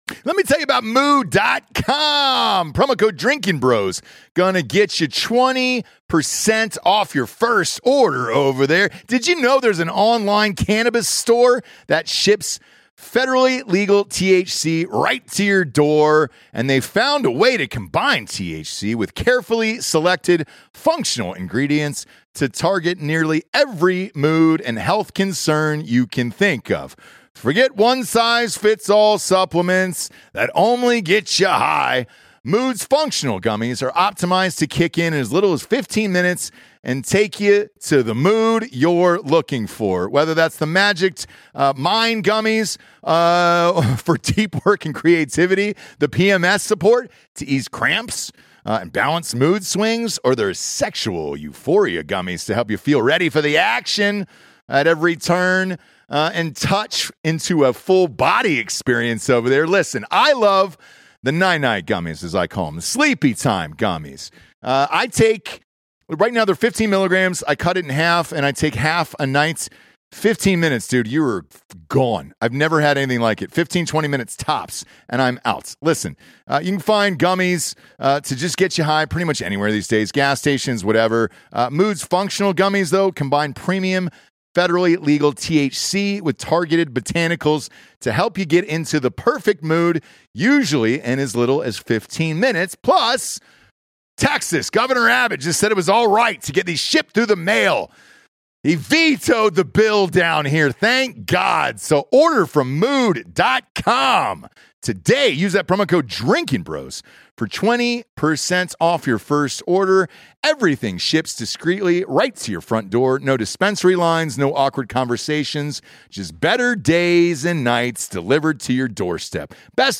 plays a harmonica tribute.